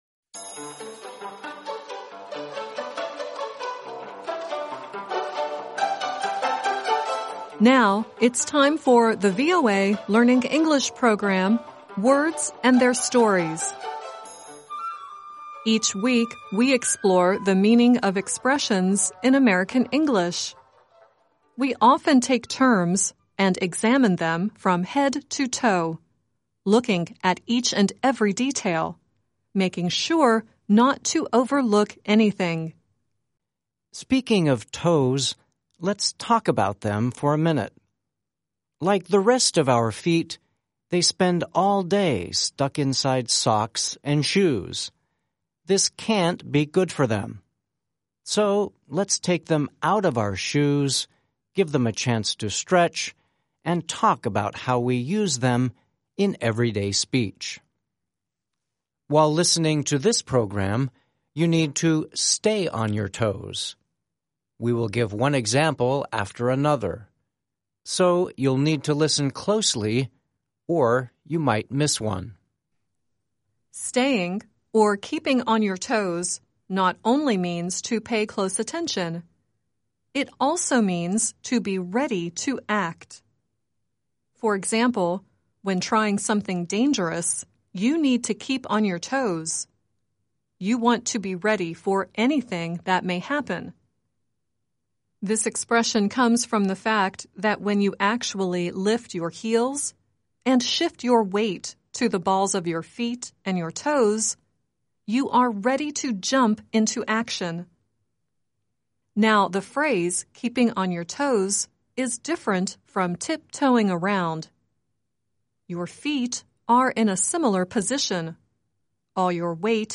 At the end of the audio story, Tiny Tim sings "Tiptoe Through the Tulips."